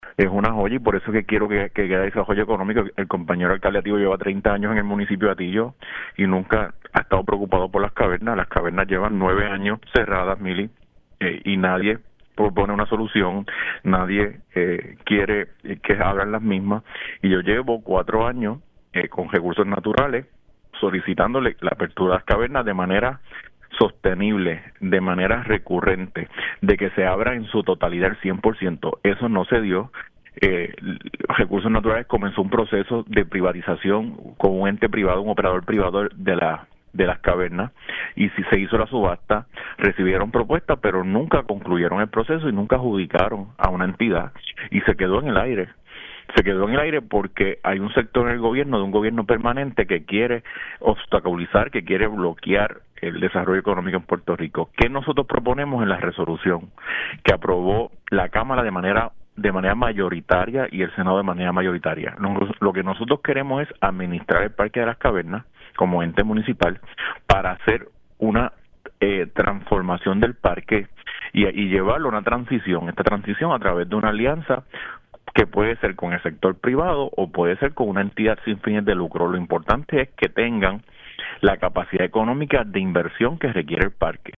Por su parte, el alcalde de Camuy, Gabriel “Gaby” Hernández opinó que Carlos Román Román no ha expresado su preocupación sobre el Parque en los 30 años que ha estado en la administración de Hatillo.
315-GABRIEL-HERNANDEZ-ALC-CAMUY-DICE-ALC-DE-HATILLO-NUNCA-HA-PROPUESTO-SOLUCION-PARA-REABRIR-PARQUE-DE-LAS-CAVERNAS.mp3